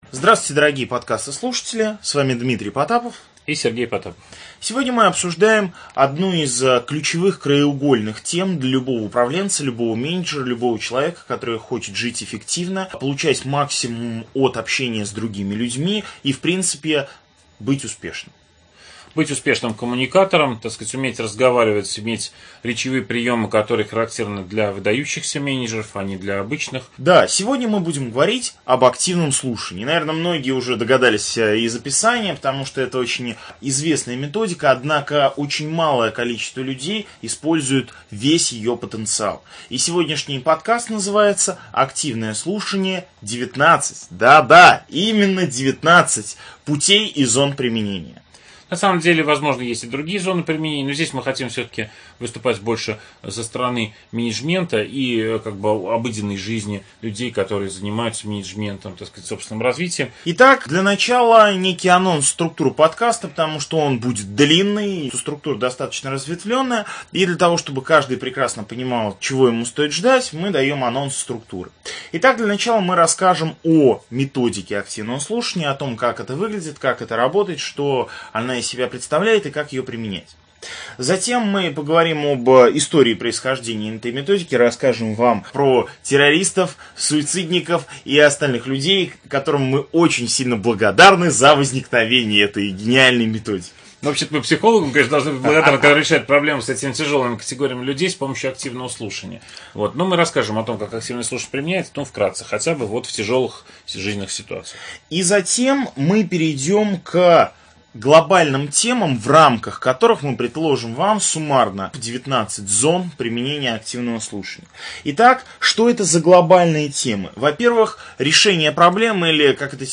Аудиокнига 19 зон применения методики «Активное слушание» | Библиотека аудиокниг